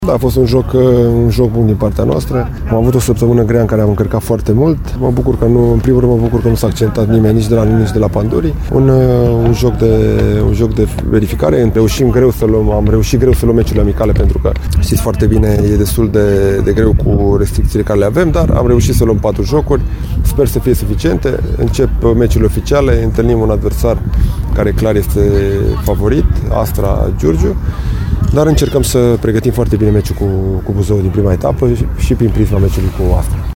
Antrenorul alb-violeților, Dan Alexa, a tras concluziile jocului de azi, cu Pandurii, dar și a perioadei de pregătire de iarnă: